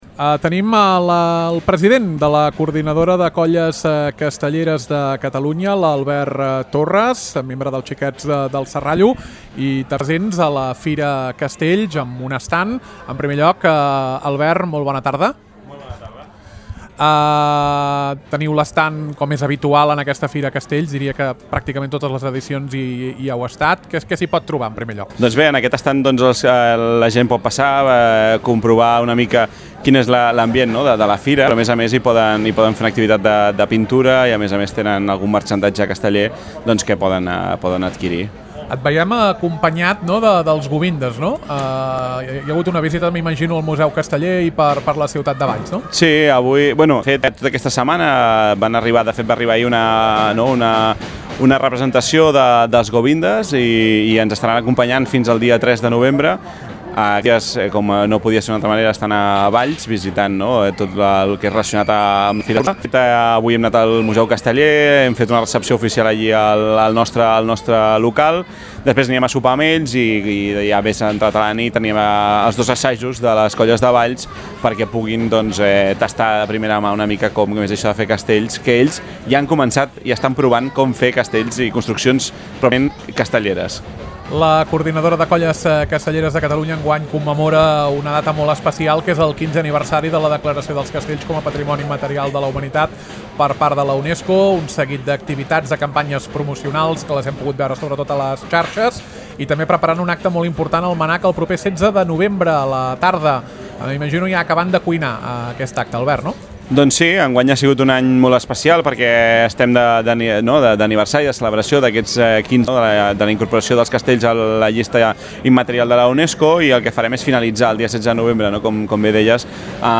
Nova entrevista a Ràdio Ciutat de Valls.